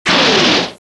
bazooka_missile.wav